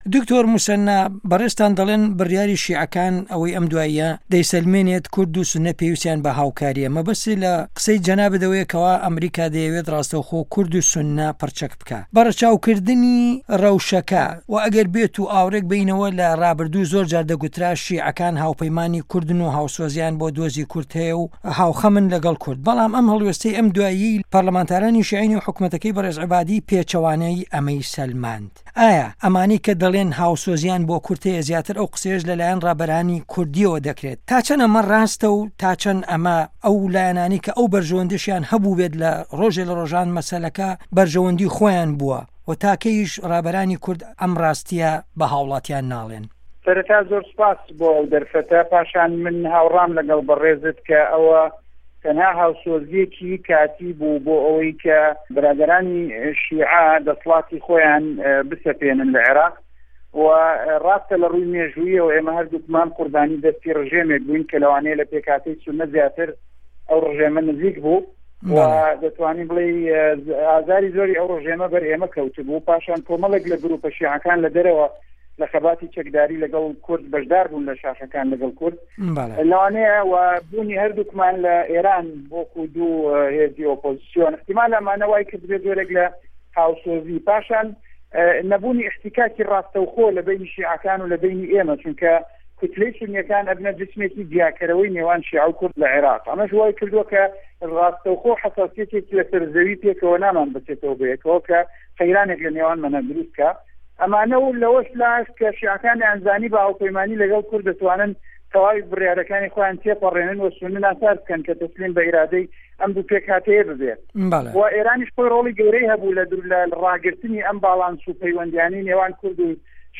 وتووێژ له‌گه‌ڵ دکتۆر موسه‌نا ئه‌مین